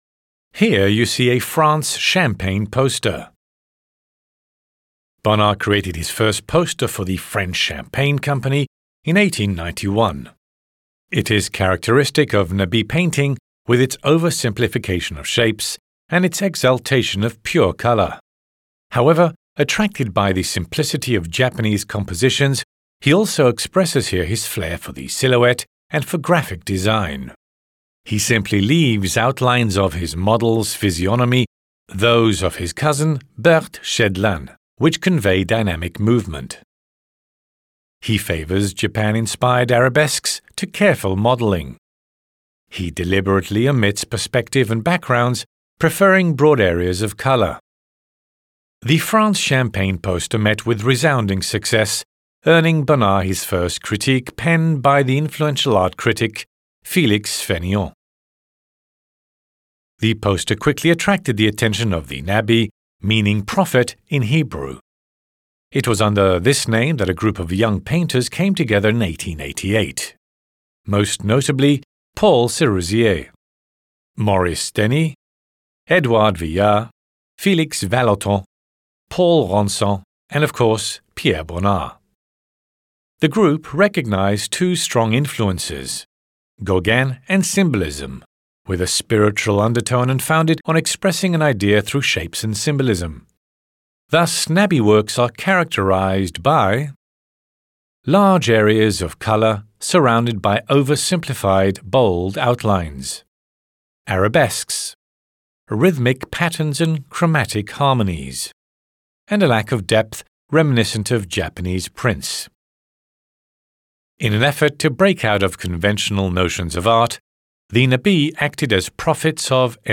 Les audioguides de la Collection